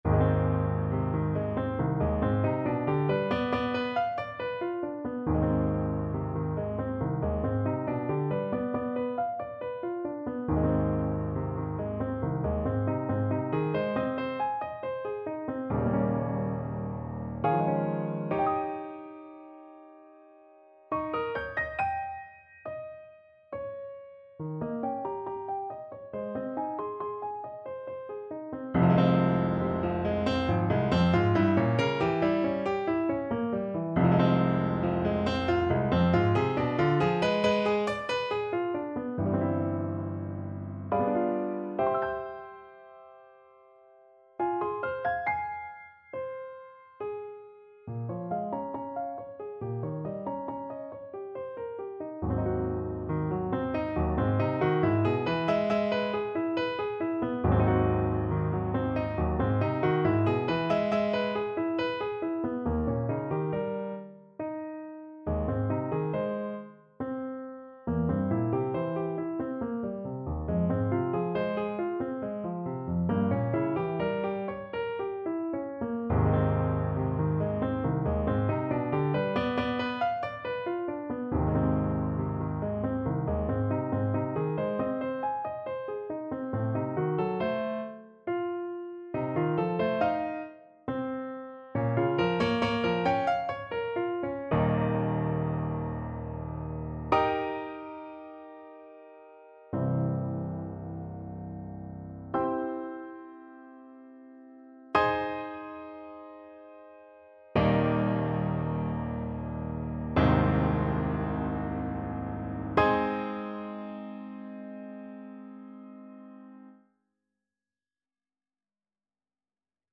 Play (or use space bar on your keyboard) Pause Music Playalong - Piano Accompaniment Playalong Band Accompaniment not yet available reset tempo print settings full screen
Ab major (Sounding Pitch) Bb major (Clarinet in Bb) (View more Ab major Music for Clarinet )
3/4 (View more 3/4 Music)
~ = 69 Large, soutenu
Classical (View more Classical Clarinet Music)